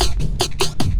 10 LOOPSD2-R.wav